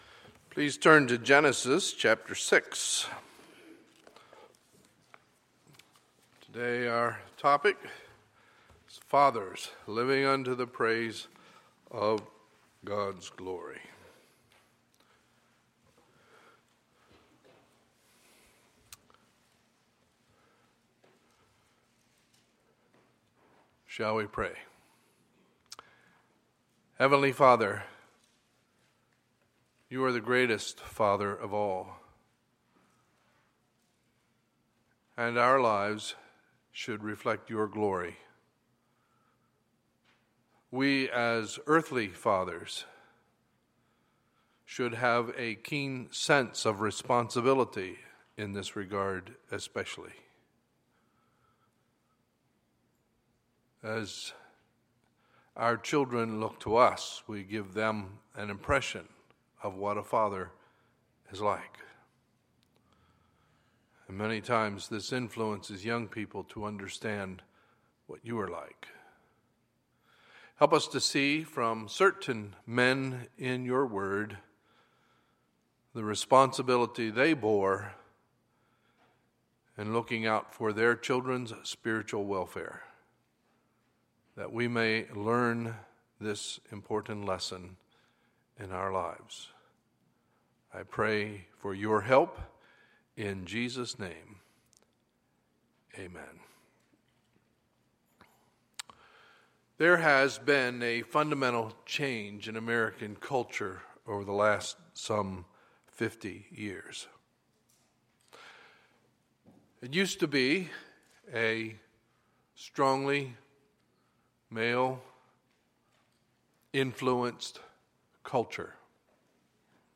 Sunday, June 19, 2016 – Sunday Morning Service